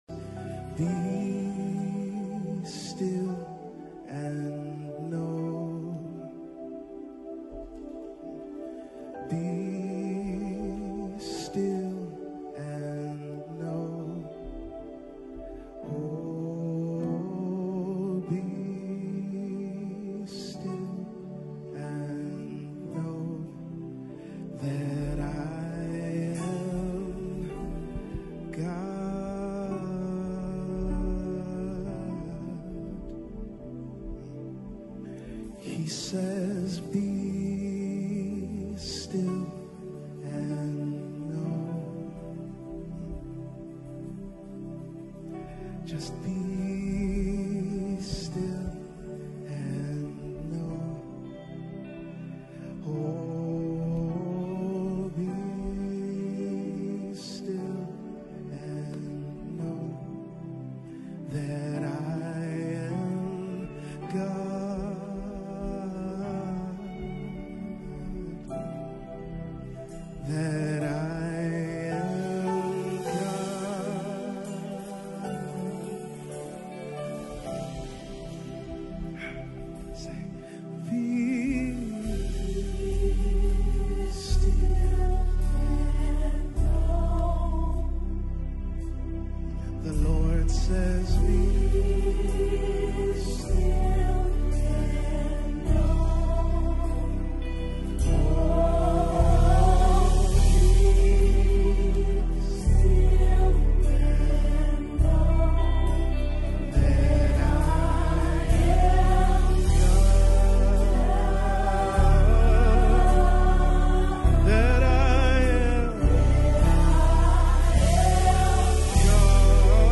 Latest Praise and Worship Gospel Music 2022.